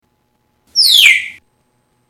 Parrot
Tags: Science/Nature Animals of China Animals Sounds China Giant Panda